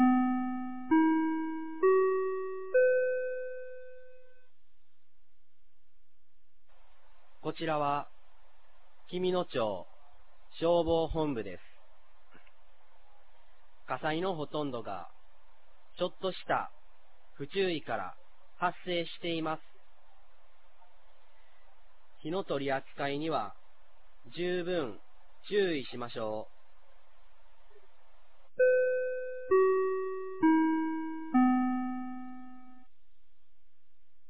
2022年06月11日 16時00分に、紀美野町より全地区へ放送がありました。